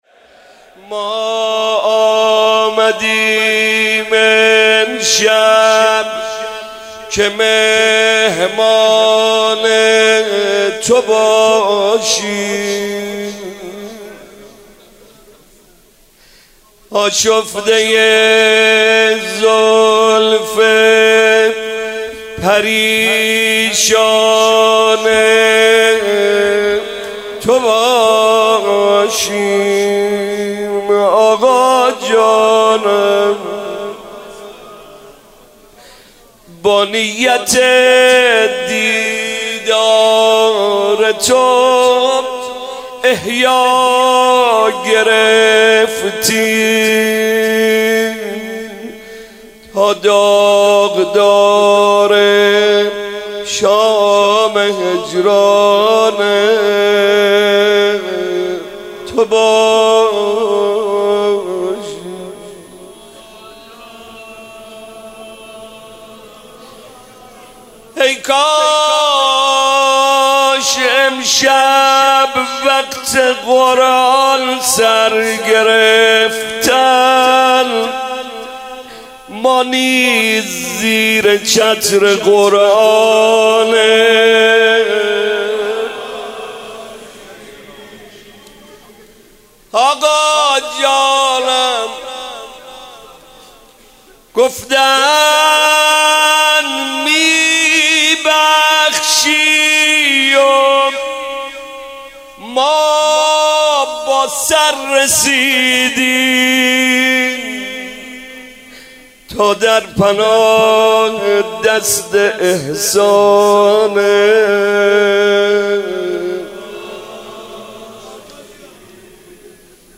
شب 23 رمضان 97 - مناجات - ما آمدیم امشب که مهمان تو باشیم